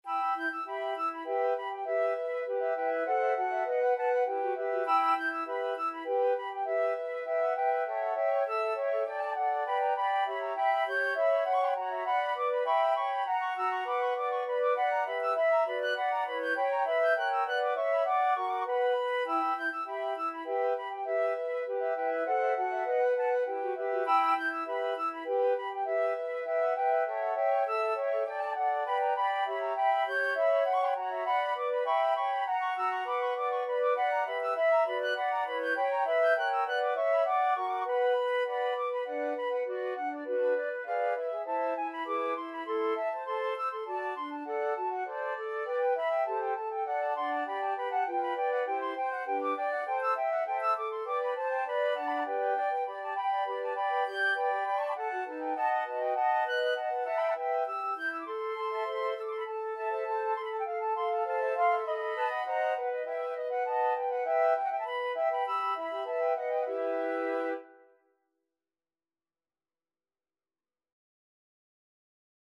Flute 1Flute 2Flute 3Flute 4
2/4 (View more 2/4 Music)
Classical (View more Classical Flute Quartet Music)